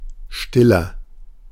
Ääntäminen
Ääntäminen Tuntematon aksentti: IPA: /ˈʃtɪ.lɐ/ Haettu sana löytyi näillä lähdekielillä: saksa Käännöksiä ei löytynyt valitulle kohdekielelle. Stiller on sanan still komparatiivi.